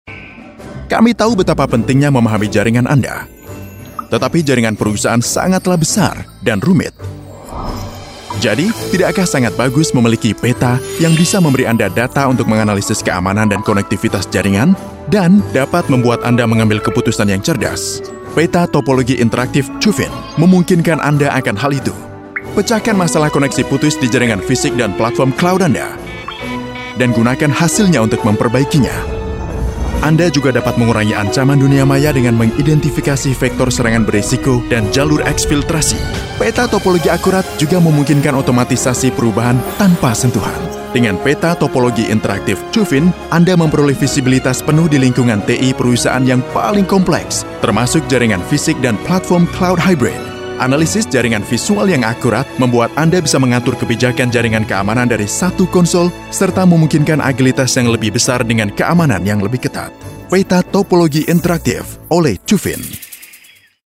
Sprechprobe: Werbung (Muttersprache):
Lite-Digital Ad_01.mp3